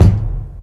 Kick [ Grindin' ].wav